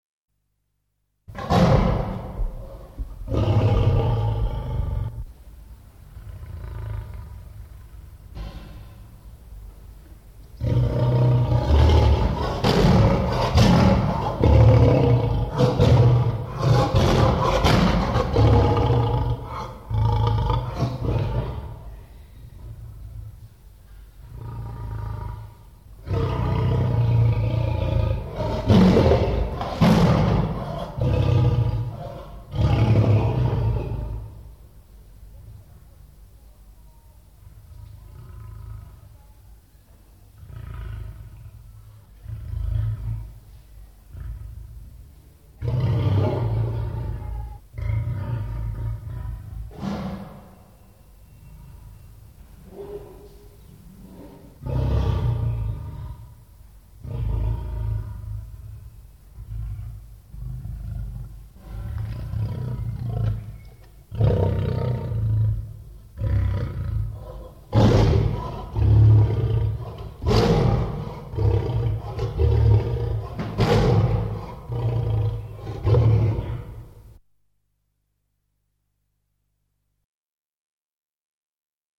leoni_lions01.mp3